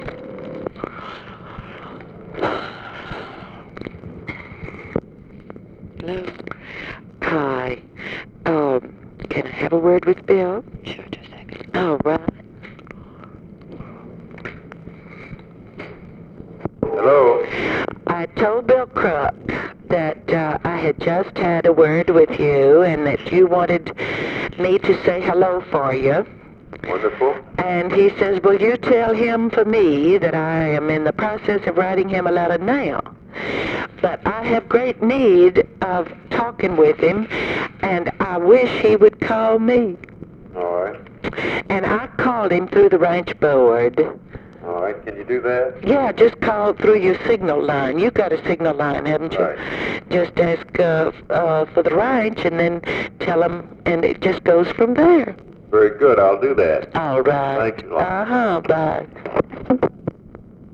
Conversation with BILL MOYERS
Secret White House Tapes | Lyndon B. Johnson Presidency